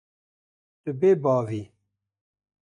Pronounced as (IPA) /beːˈbɑːv/